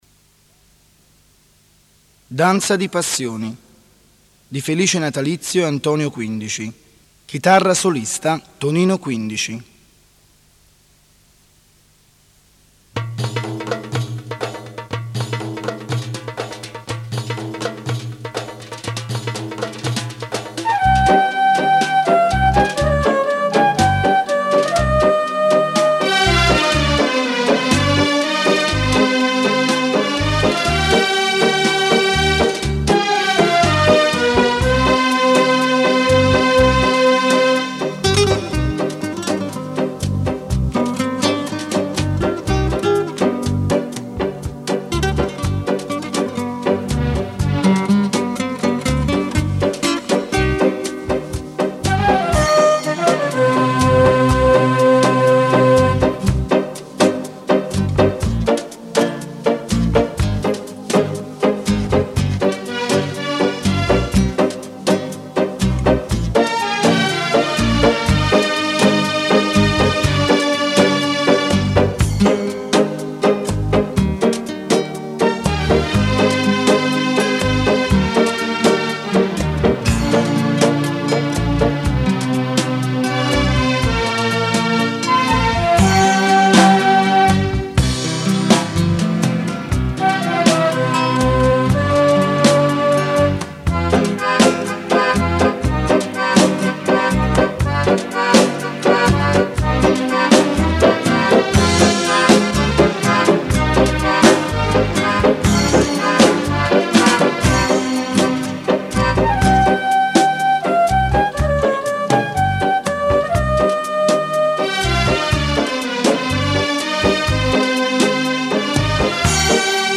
versione strumentale